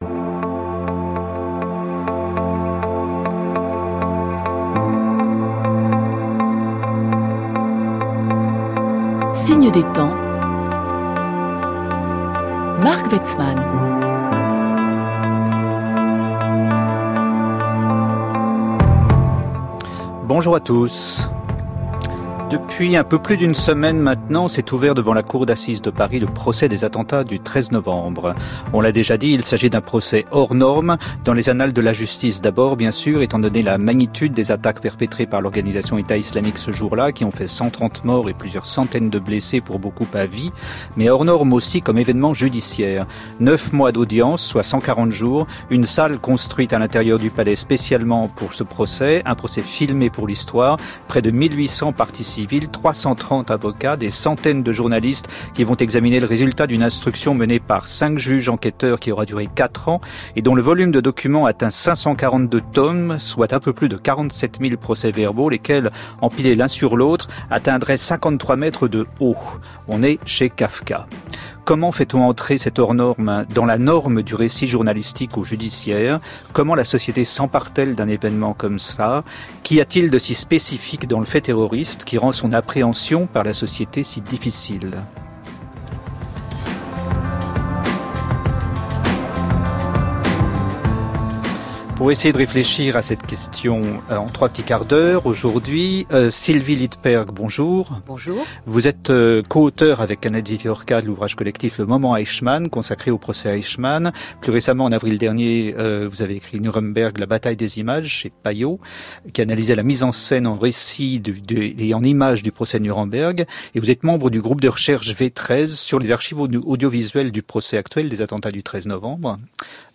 Emission « Signes des temps » de Marc Weitzman diffusée sur France culture: les problématiques discutées sur la nature et les enjeux des différentes formes de terrorisme et donc sur les manière de les traiter judiciairement et d’en rendre compte dans les médias.